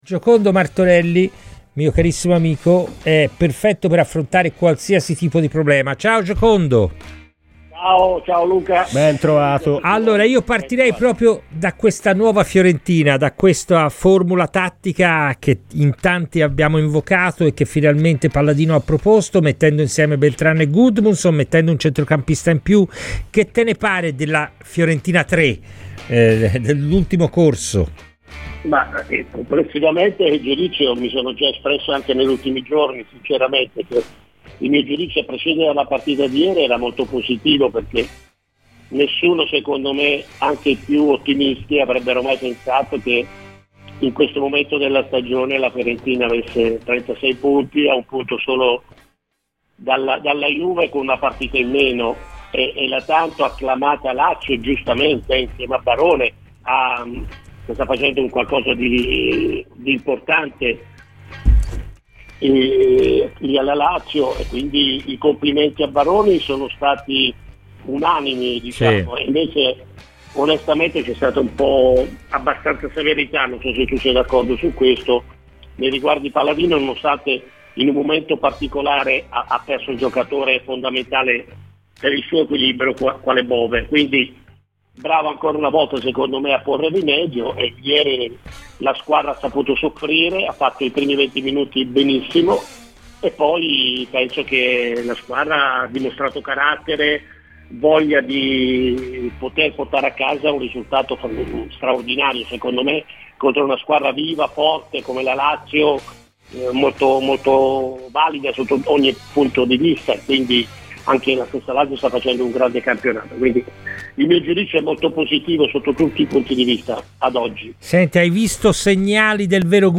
è intervenuto a Radio FirenzeViola durante la trasmissione "Palla al centro" per analizzare il mercato gigliato.